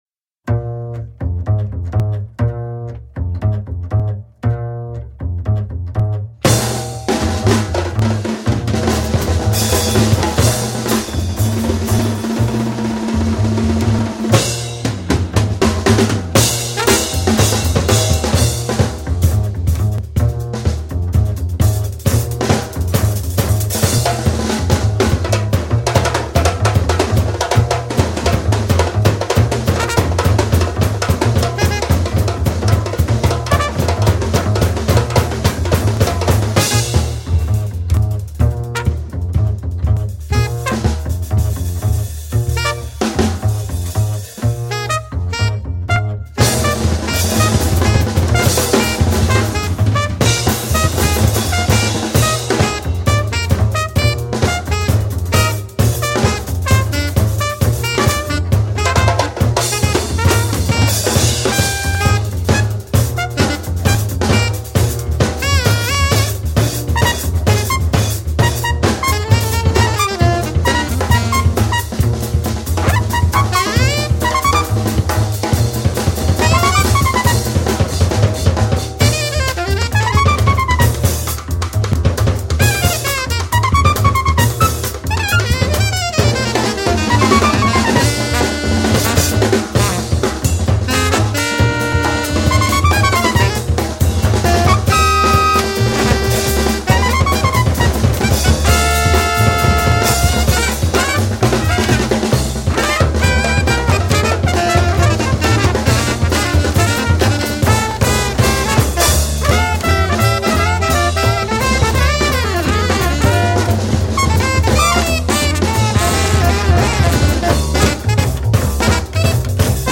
bere iritziak azaldu ditu saio honetan festibalean egon ziren bere artista gustokoenen musikarekin tartekatuta